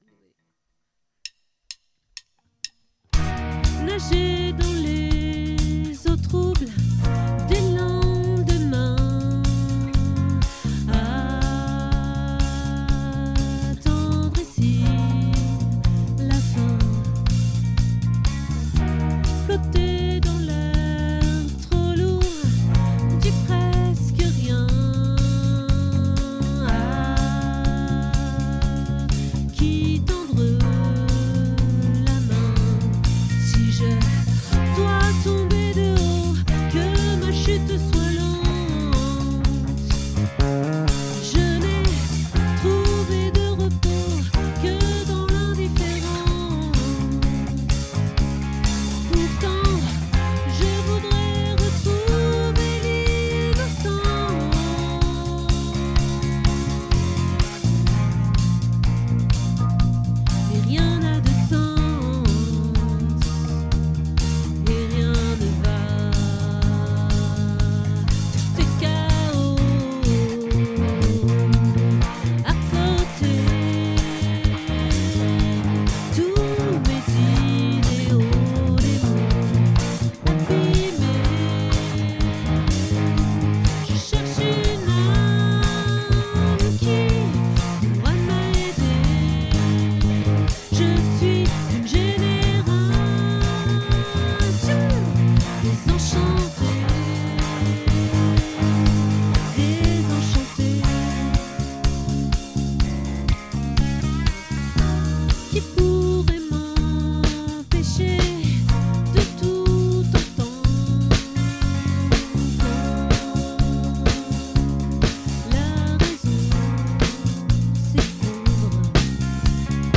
🏠 Accueil Repetitions Records_2025_12_15